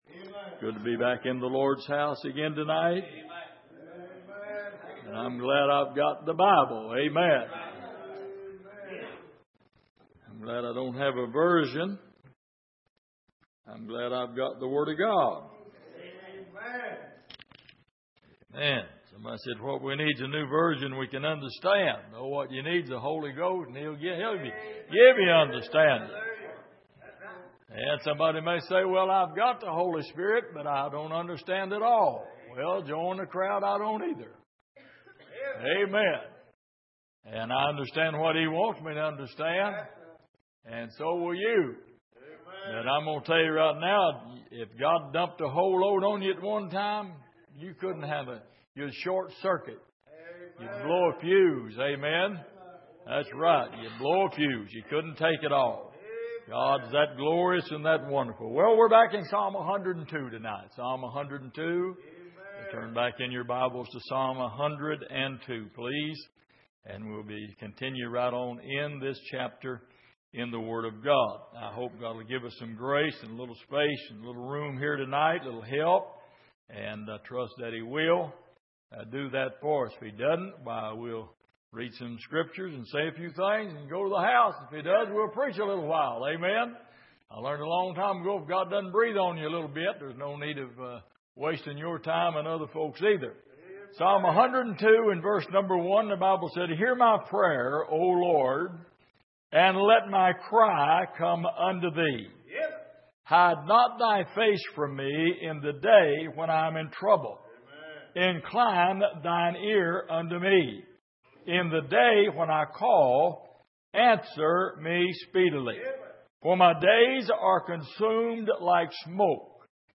Passage: Psalm 102:1-11 Service: Sunday Evening